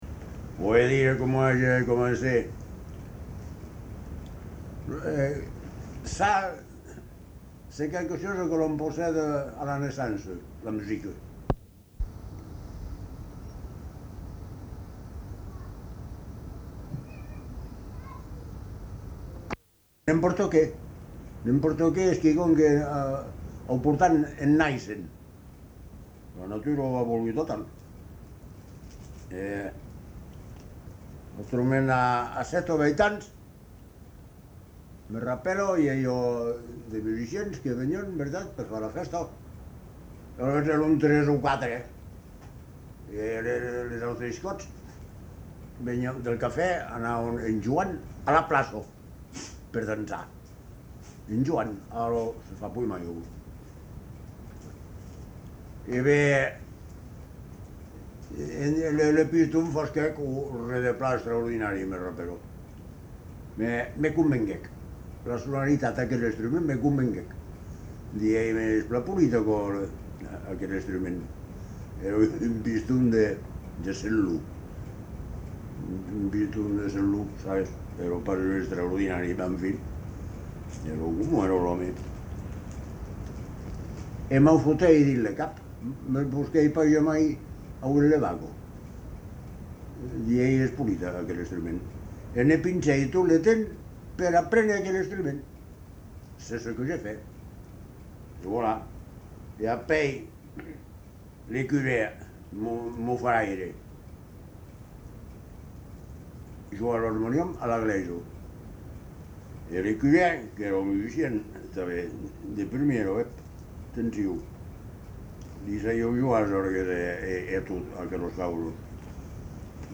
Lieu : Montgaillard-Lauragais
Genre : récit de vie